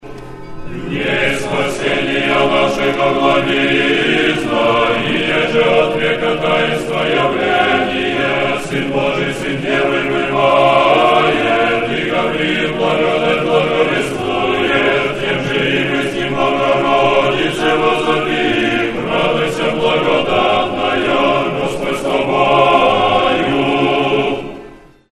Тропарь Благовещения Пресвятой Богородицы